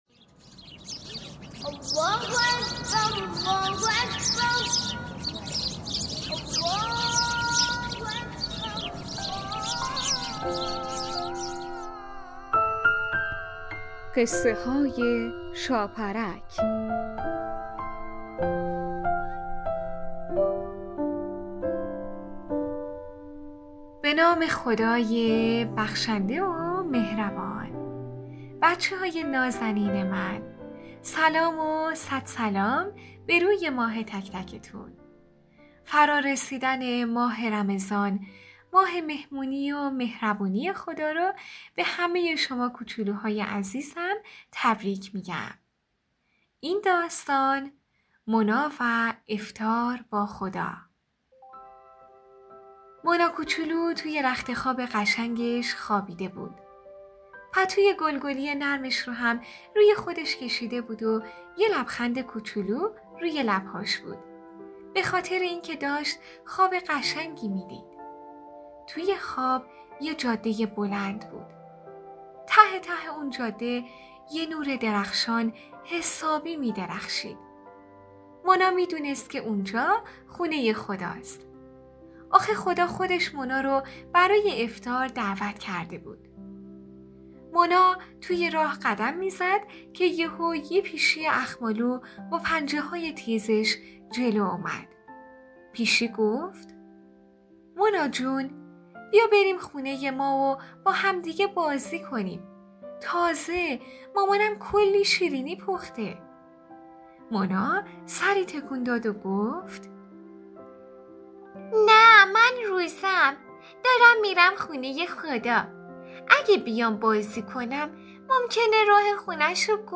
قسمت صد و شصت و پنجم برنامه رادیویی قصه های شاپرک با نام مونا و افطار با خدا داستان کودکانه با موضوعیت نمازی